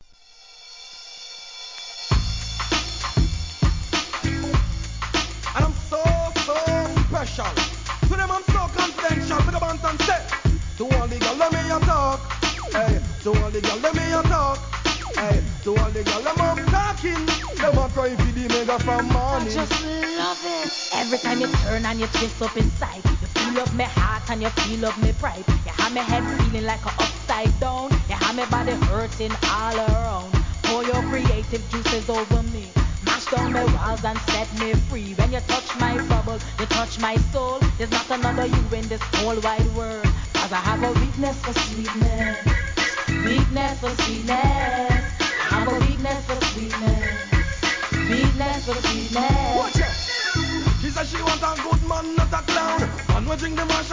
REGGAE
フィメールDeeJay!!